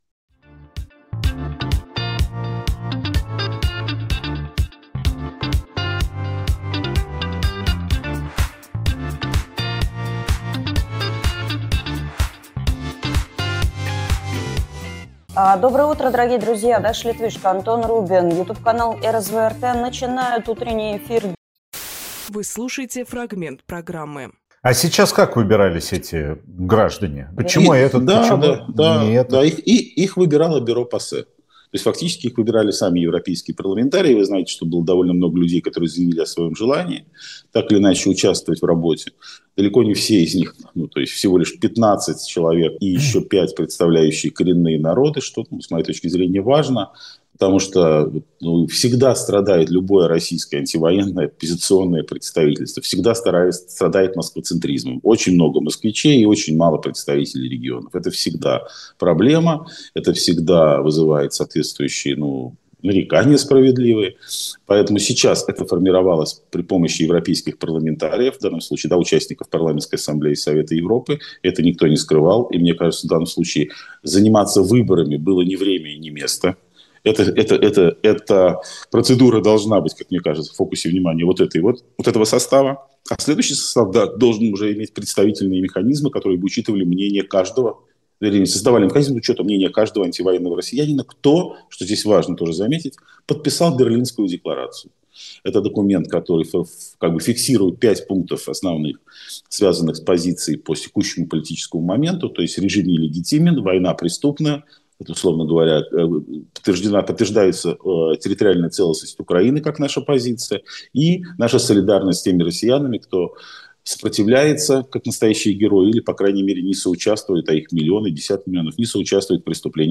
Фрагмент эфира от 27.01.26